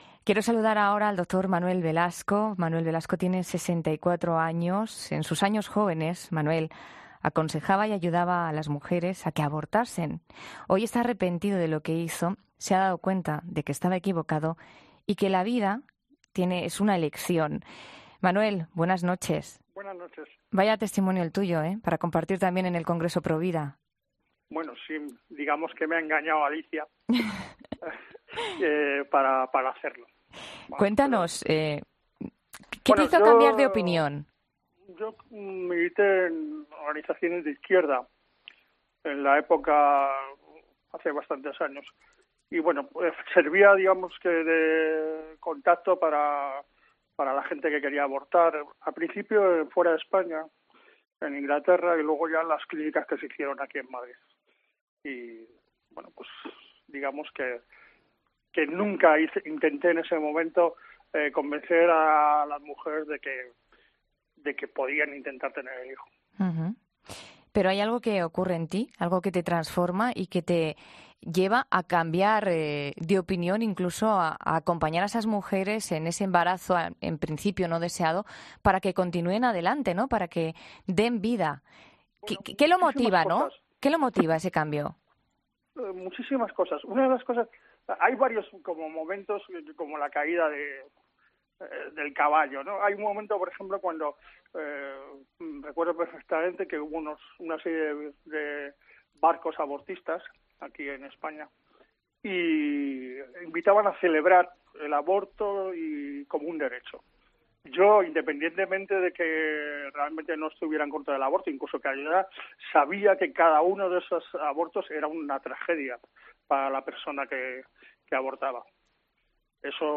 En 'La Noche de COPE' hablamos con algunos testimonios que participarán en el Congreso Provida de Madrid.